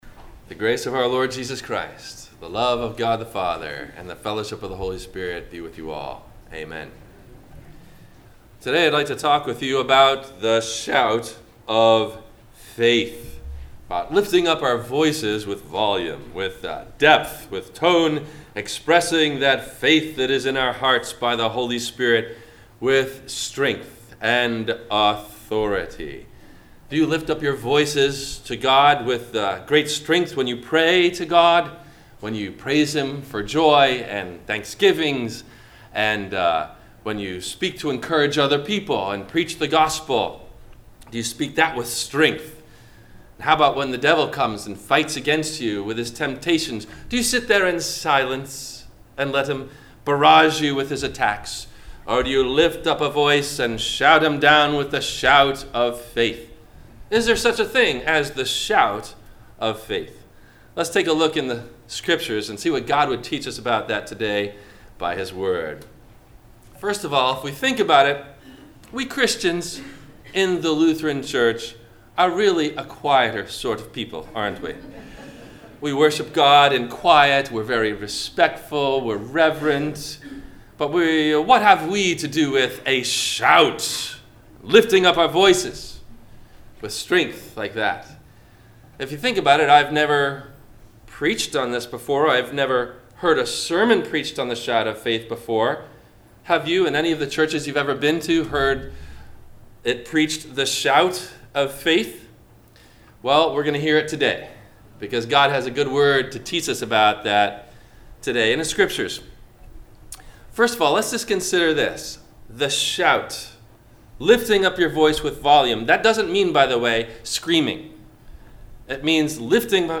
The Shout of Faith - Sermon - February 16 2020 - Christ Lutheran Cape Canaveral